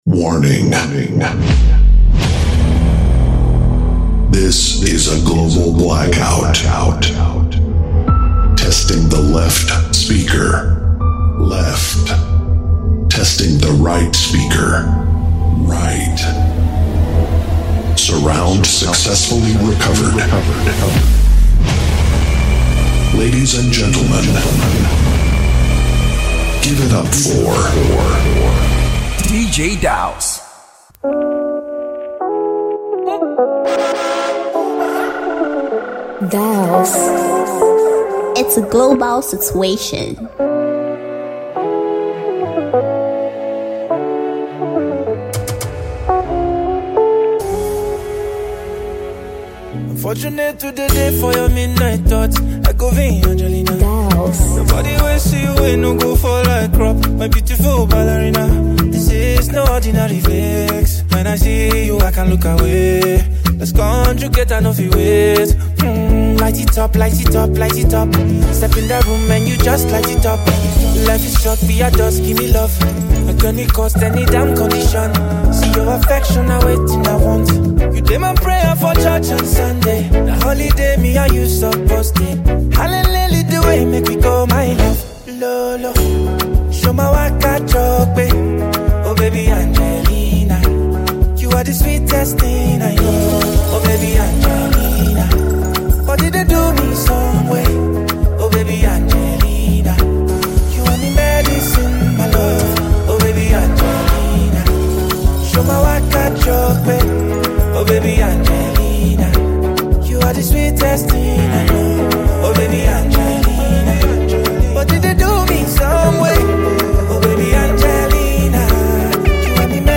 Enjoy this amazing mixtape.
Ghana Afrobeats MP3